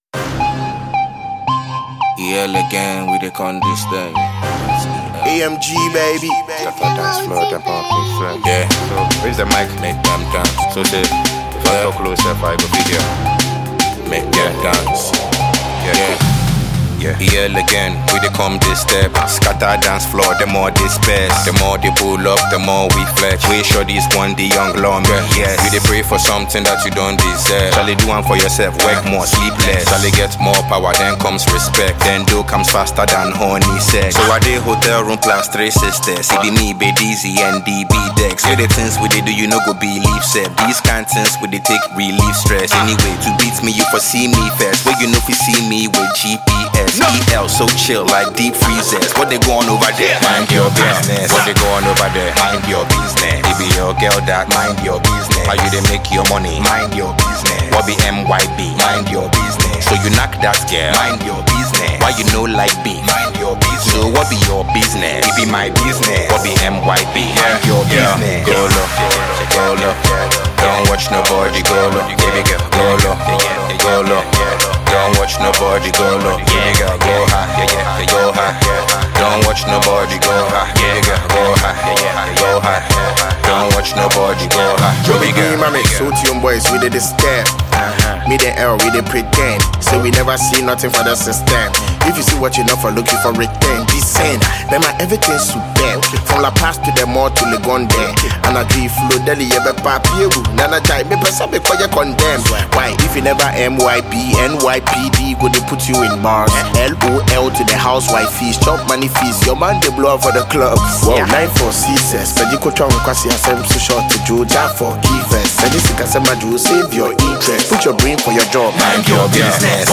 Ghanaian hip-hop